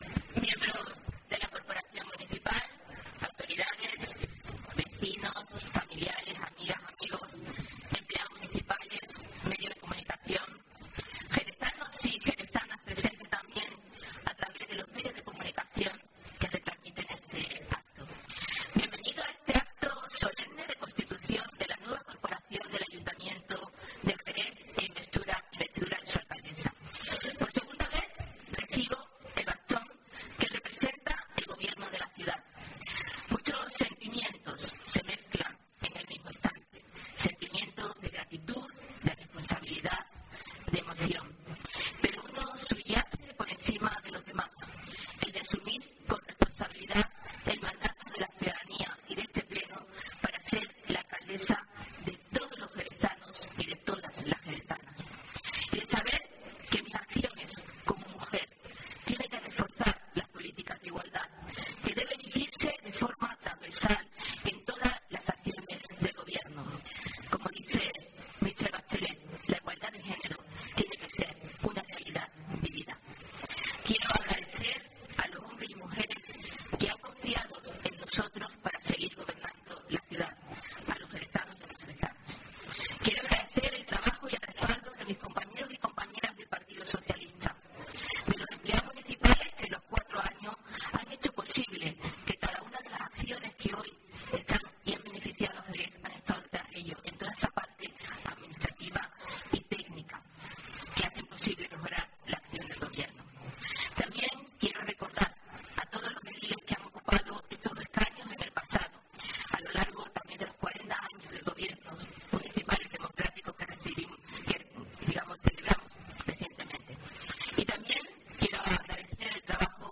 Discurso de investidura de Mamen Sánchez